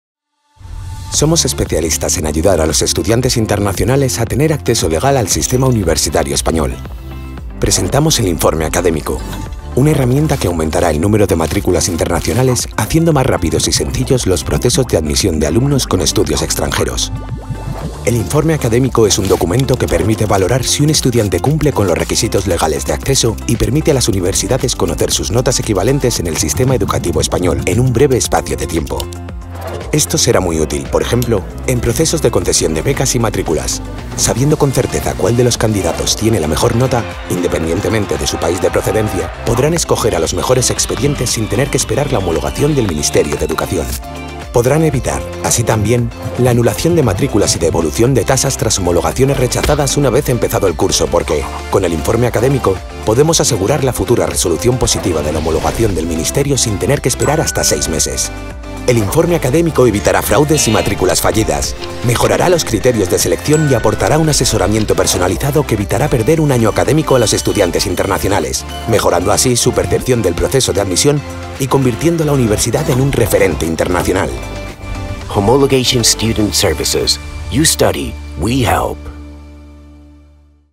Corporativo Corporate -